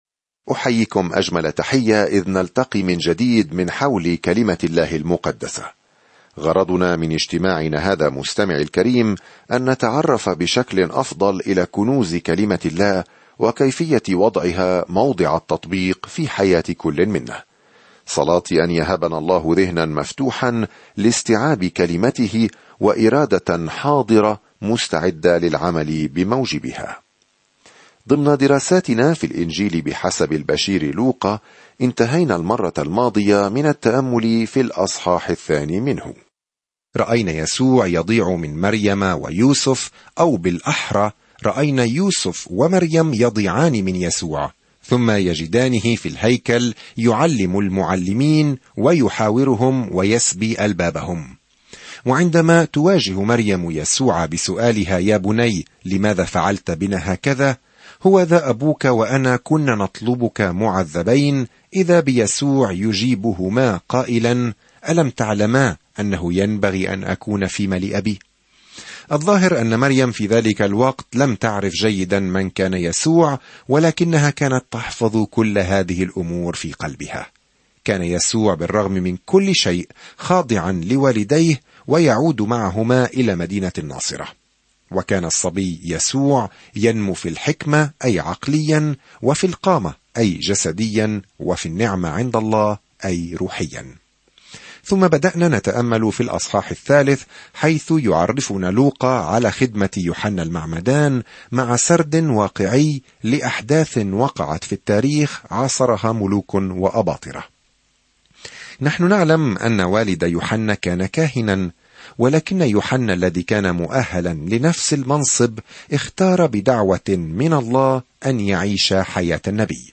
الكلمة لُوقَا 9:3-38 لُوقَا 1:4-13 يوم 5 ابدأ هذه الخطة يوم 7 عن هذه الخطة تابع رحلتك عبر الكتاب المقدس بخير باستخدام خطة الدراسة الصوتية للوقا، التالية في الستار: ابدأ الآن!ينقل شهود العيان الأخبار السارة التي يرويها لوقا عن قصة يسوع منذ الولادة وحتى الموت وحتى القيامة؛ ويعيد لوقا أيضًا سرد تعاليمه التي غيرت العالم. سافر يوميًا عبر لوقا وأنت تستمع إلى الدراسة الصوتية وتقرأ آيات مختارة من كلمة الله.